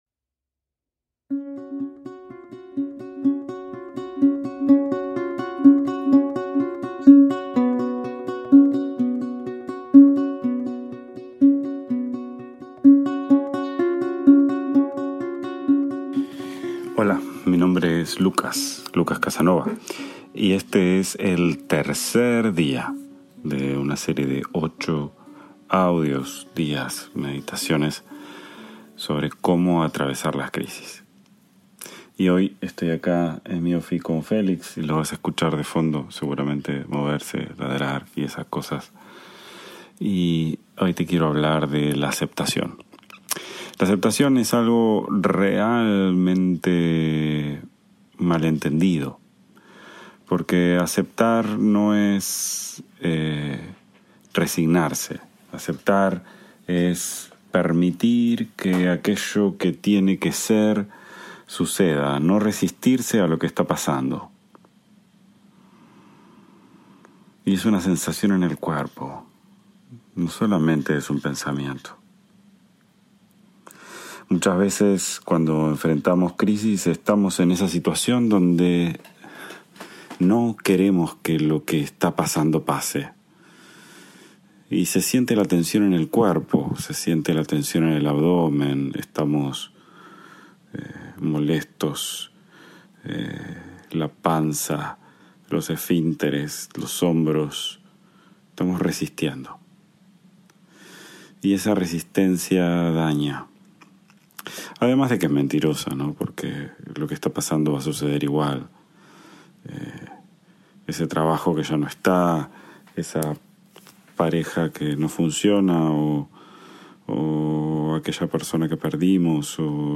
IMPORTANTE: Esta serie fue grabada durante una época de muchos viajes, directamente en mi teléfono móvil. La calidad del audio no está a la altura de lo que escucharás en las siguientes series y episodios.